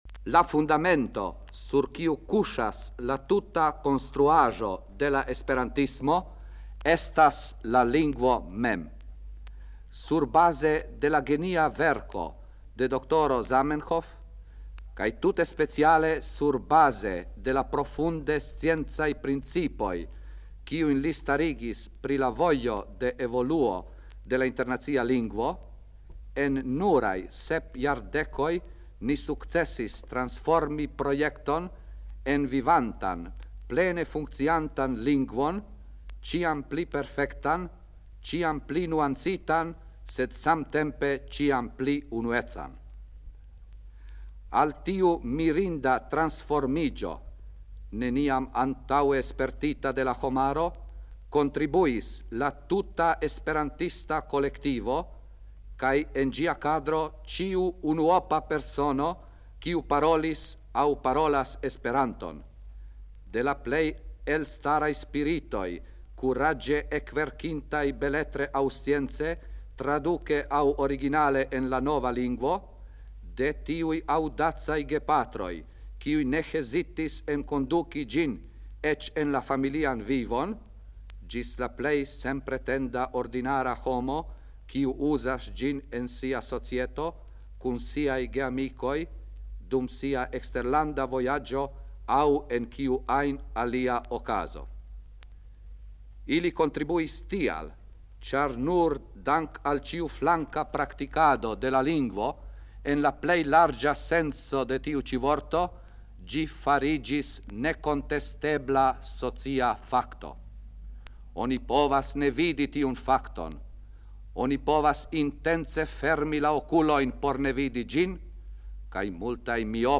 39-a UK en Harlem, Nederlando, 1954."La Kultura Valoro de la Internacia Lingvo"